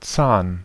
Ääntäminen
IPA: [tsaːn] IPA: /t͡saːn/